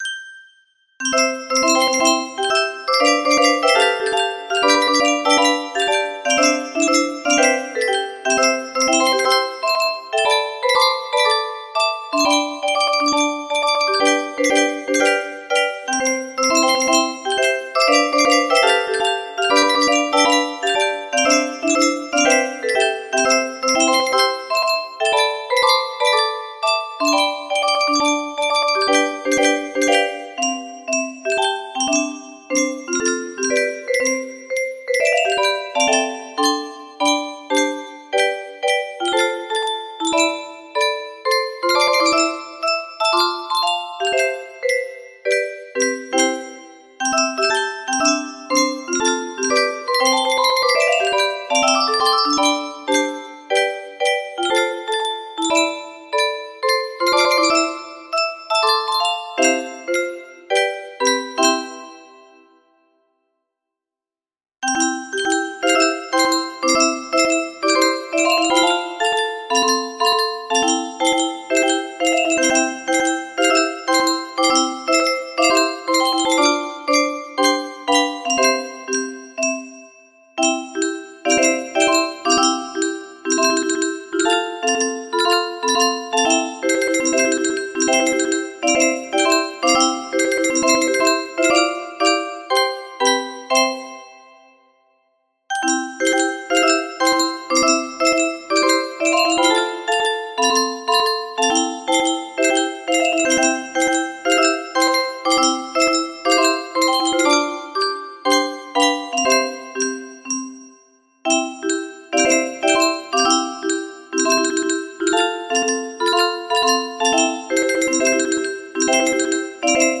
music box melody
Import causes severe quantized artifacts.
Only Note ONs are created as tines decay fast